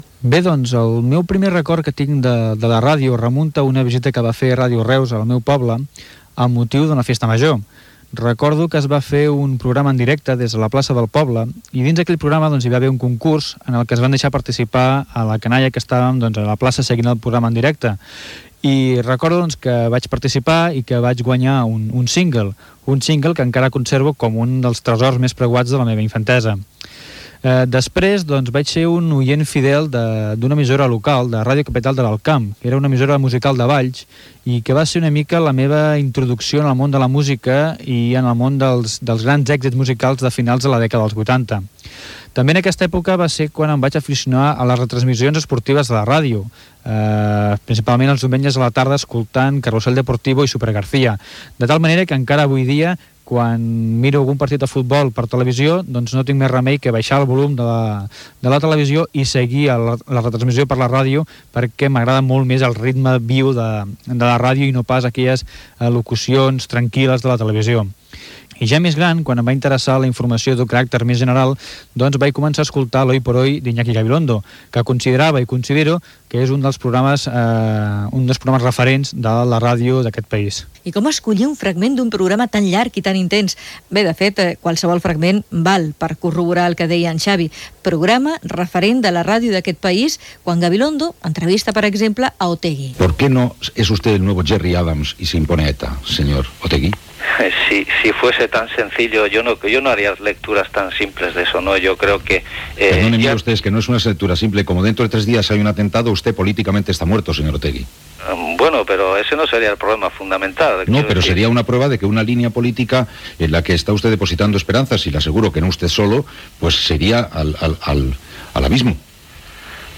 S'incliu una entrevista d'Iñaki Gabilondo al polític Arnaldo Otegui.
Divulgació